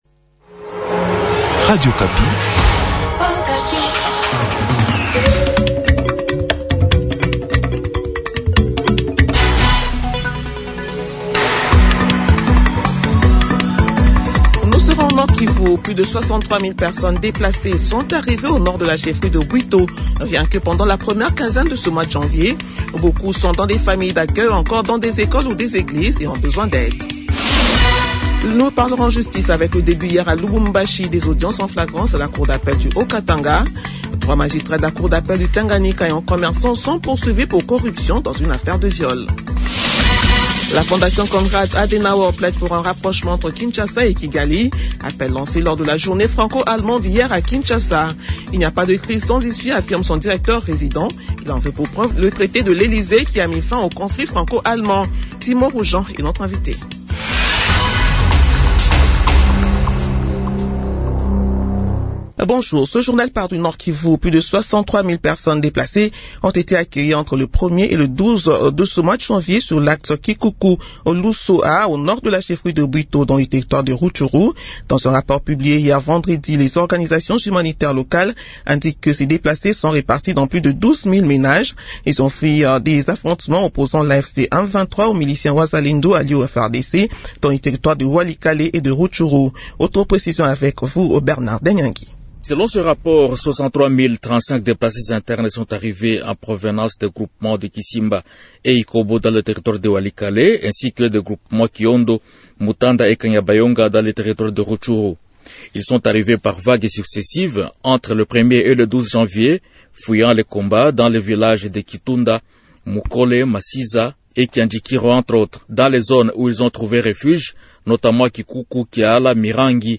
Journal midi de samedi 24 janvier